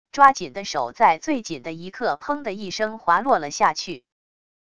抓紧的手在最紧的一刻砰地一声滑落了下去wav音频生成系统WAV Audio Player